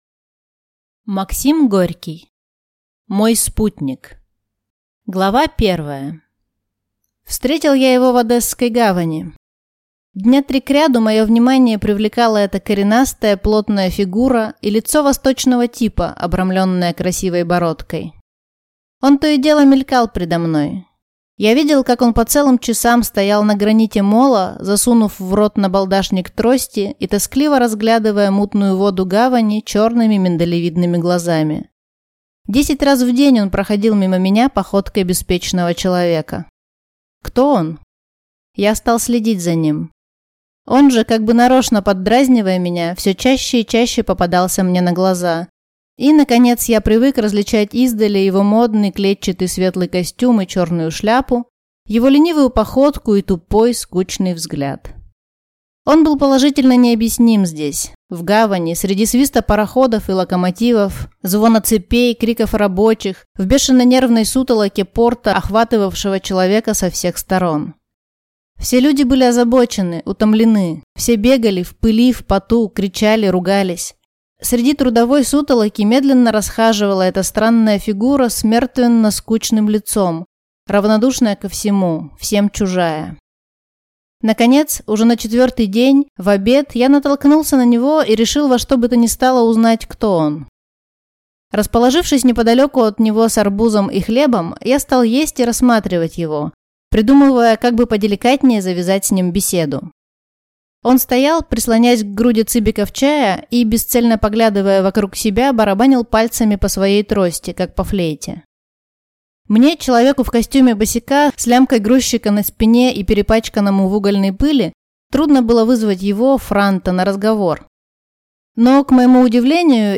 Aудиокнига Мой спутник